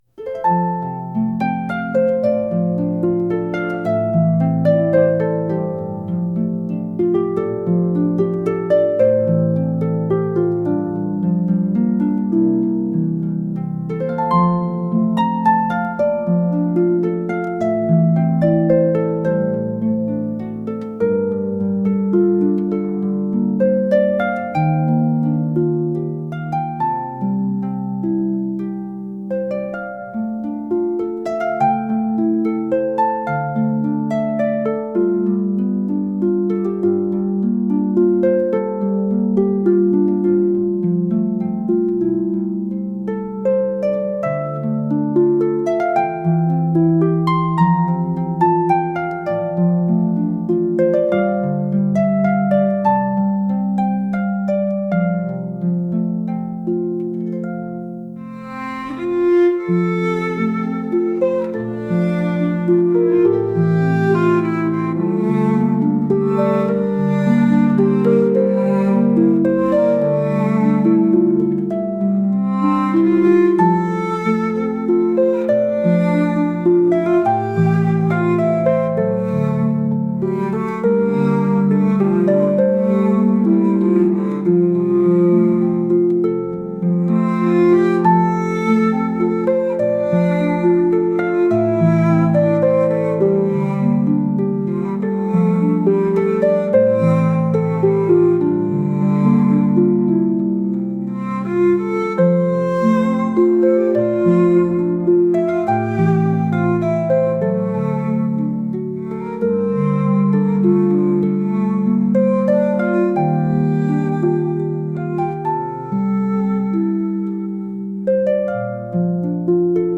誰かと再会した時に流れるようなハープとバイオリンの曲です。